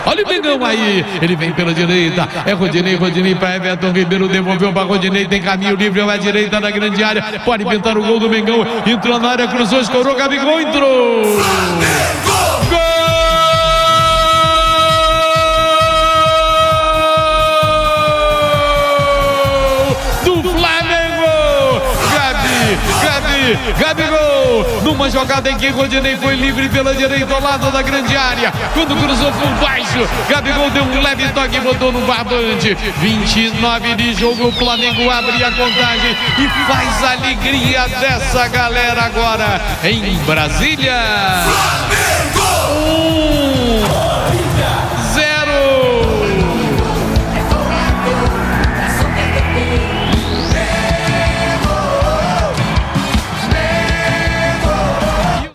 Ouça os gols da goleada do Flamengo sobre o Olimpia na Libertadores com a narração de Garotinho